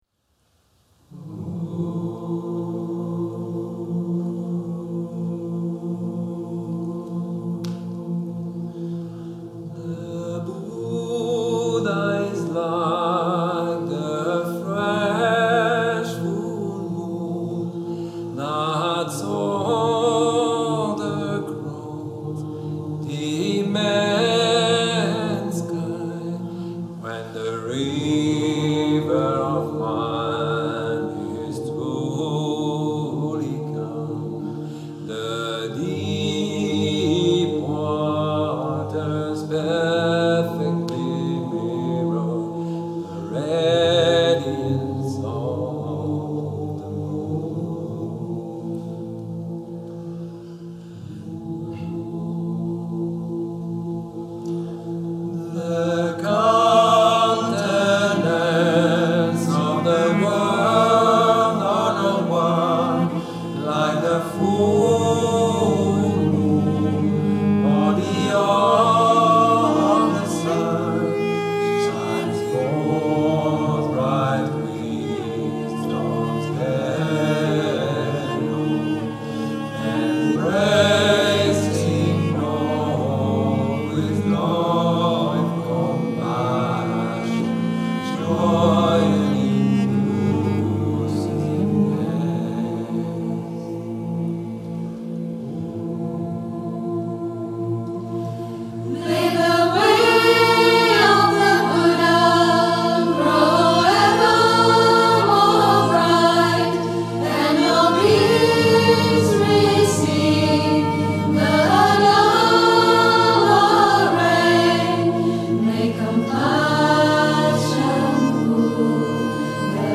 Cantos y Canciones